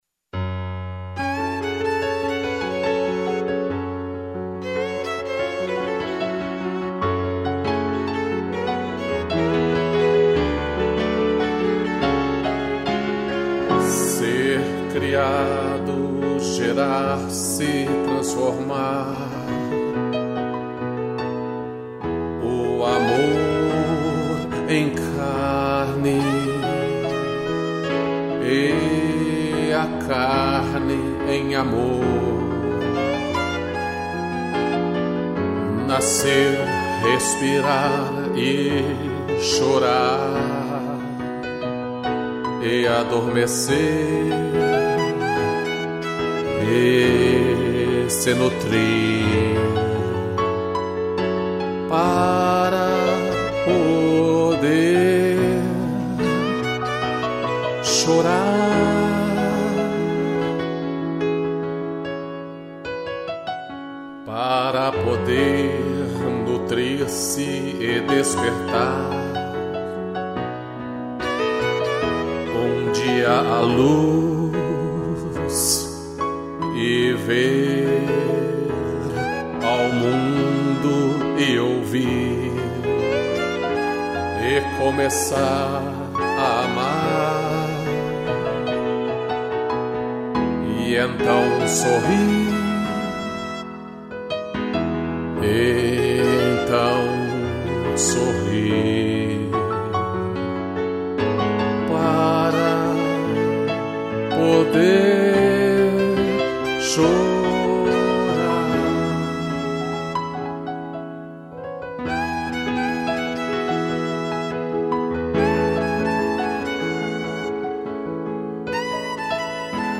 2 pianos e violino